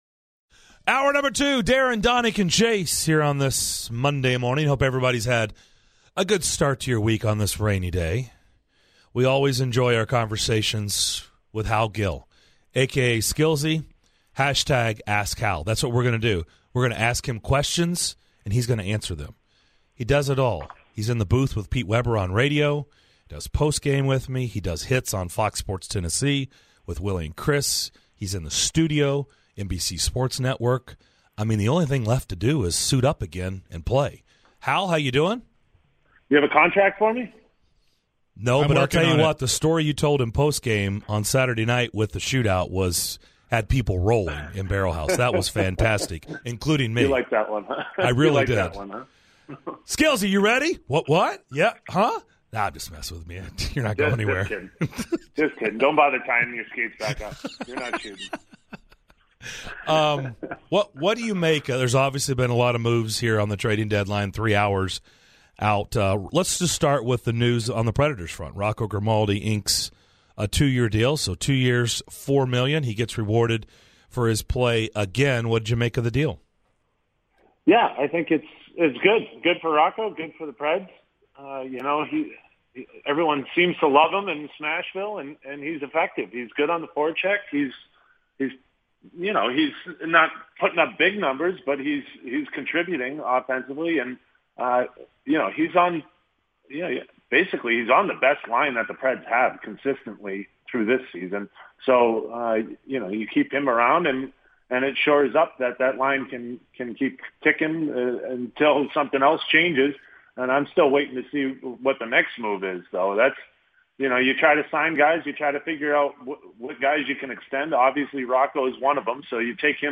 Preds radio analyst Hal Gill joined DDC to discuss the Preds and what it's like to be a player traded at the deadline.